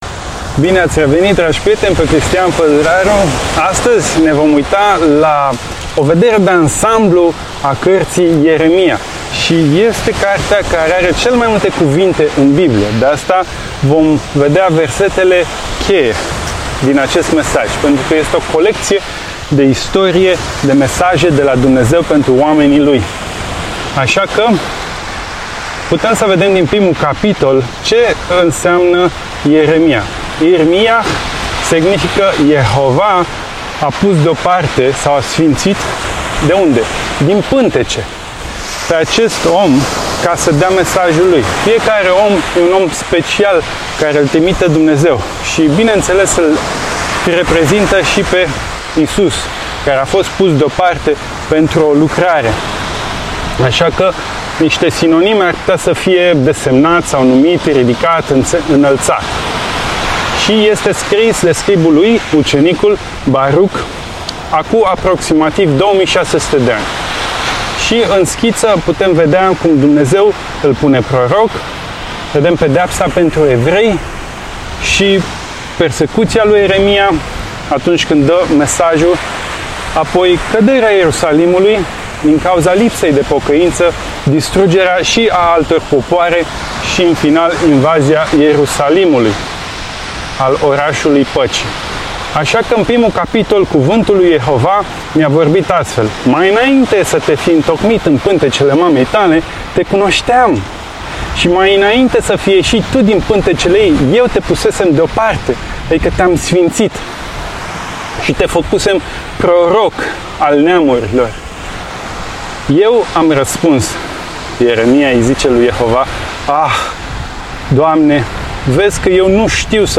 Predica Ieremia